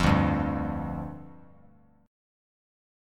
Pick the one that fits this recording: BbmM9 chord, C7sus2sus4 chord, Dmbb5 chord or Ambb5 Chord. Dmbb5 chord